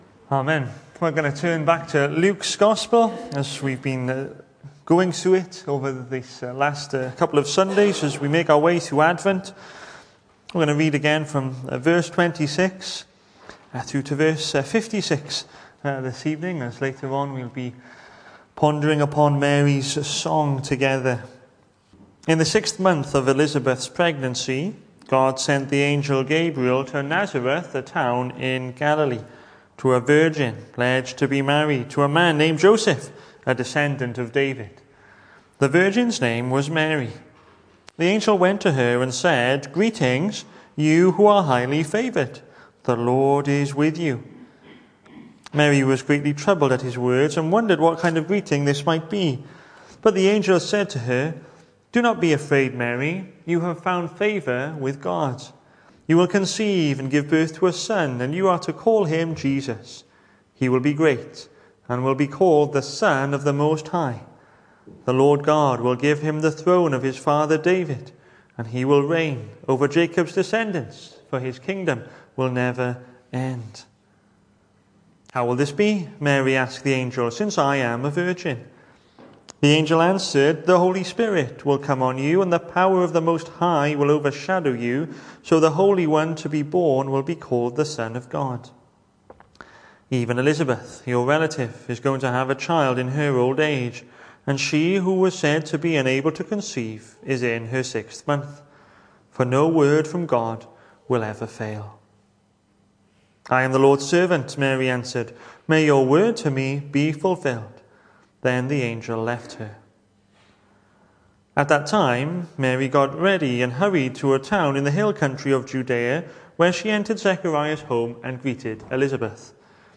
Hello and welcome to Bethel Evangelical Church in Gorseinon and thank you for checking out this weeks sermon recordings. The 8th of December saw us hold our evening service from the building, with a livestream available via Facebook.